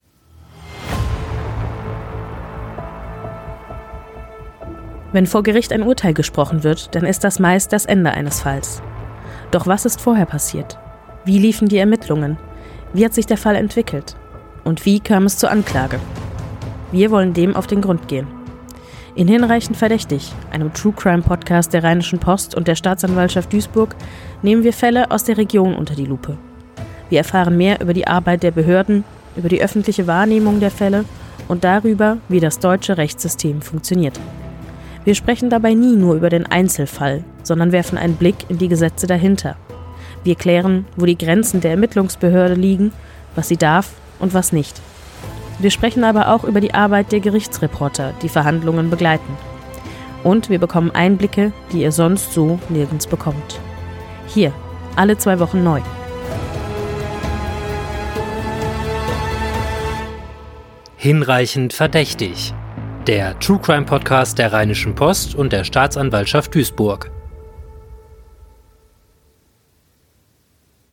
Pro Episode besprechen ein Gerichtsreporter und ein Vertreter der Staatsanwaltschaft Duisburg einen Fall: Was ist passiert?